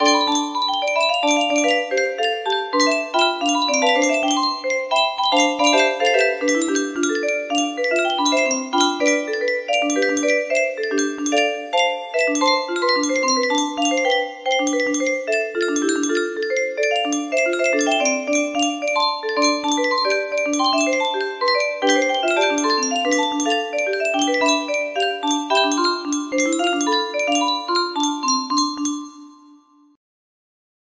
Melody 1 music box melody